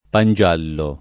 vai all'elenco alfabetico delle voci ingrandisci il carattere 100% rimpicciolisci il carattere stampa invia tramite posta elettronica codividi su Facebook pangiallo [ pan J# llo ] o pan giallo [ id. ] s. m. (gastron.)